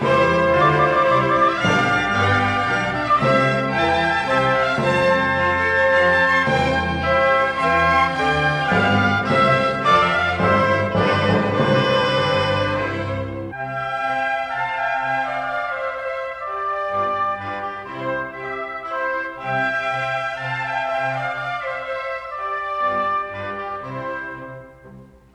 This is the Menuet in a performance by the